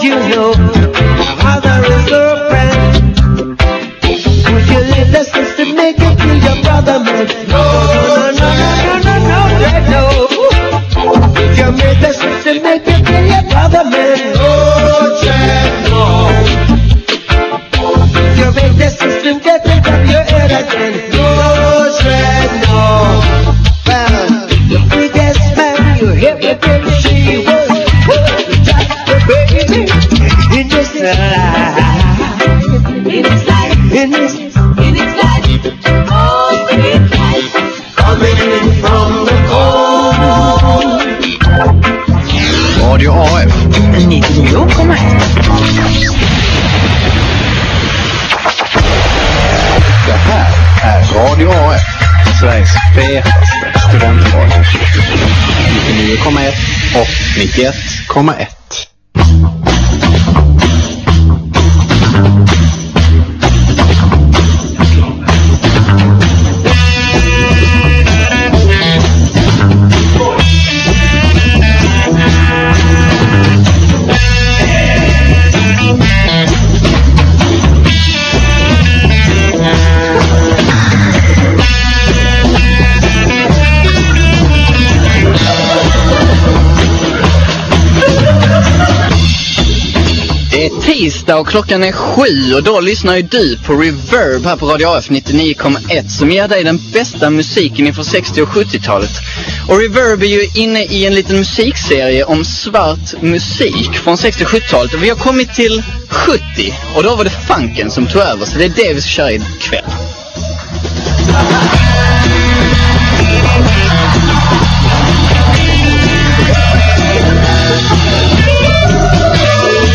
Lite råare, lite långsammare, lite sexigare. Följ med Reverb till funkens guldera.